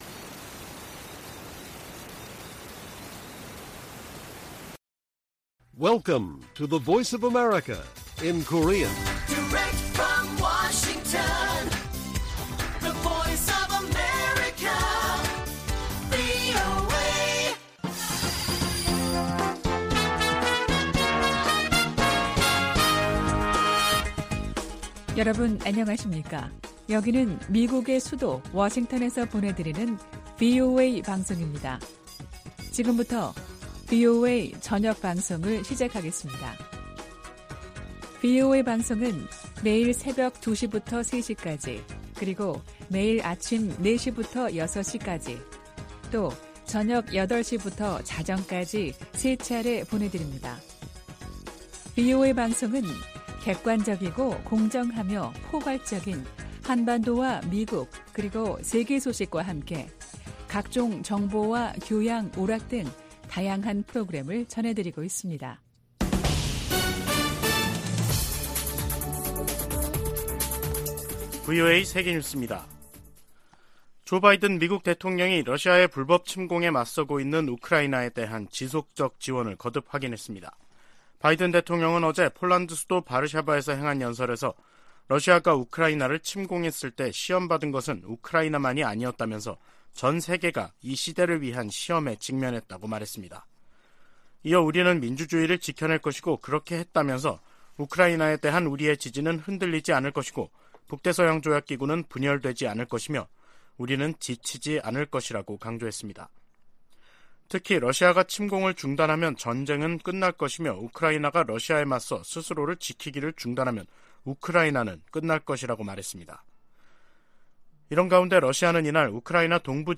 VOA 한국어 간판 뉴스 프로그램 '뉴스 투데이', 2023년 2월 22일 1부 방송입니다. 미 하원 군사위원장이 북한의 미사일 위협에 맞서 본토 미사일 방어망을 서둘러 확충할 것을 바이든 행정부에 촉구했습니다. 한국 군 당국은 북한이 대륙간탄도미사일(ICBM)의 모든 발사 능력을 보유하고 있고 정상각도 시험발사 시점을 재고 있는 단계라고 밝혔습니다. 영국과 프랑스, 독일을 비롯한 주요 국가들이 북한의 ICBM 발사를 일제히 규탄했습니다.